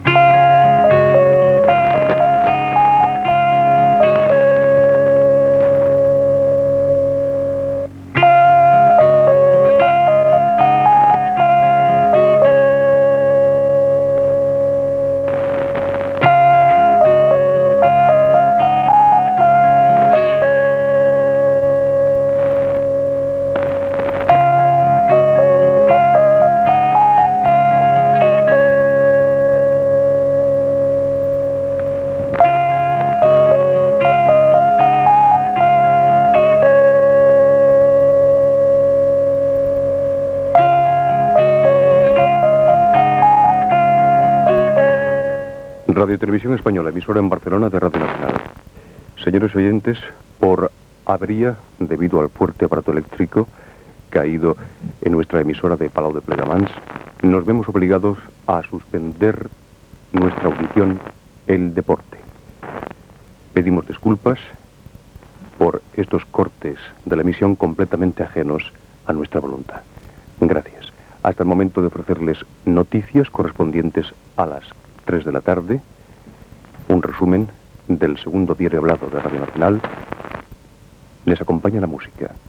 Sintonia, identificació i avís de la suspensió de l'emissió degut a una tempesta elèctrica.